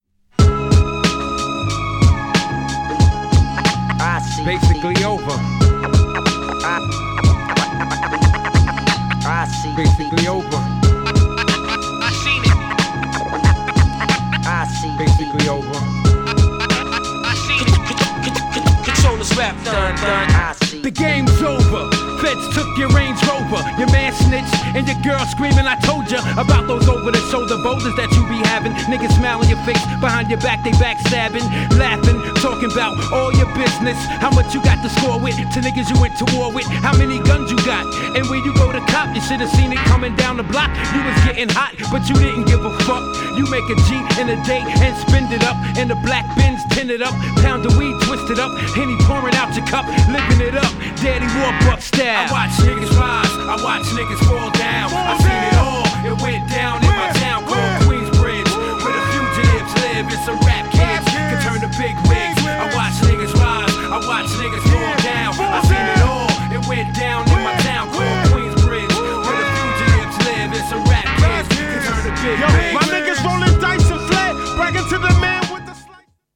もシリアスでカッコイイ!!
GENRE Hip Hop
BPM 91〜95BPM
# スリリングなトラック # ブレイクもキマッてます # メランコリックなネタ # 男汁全開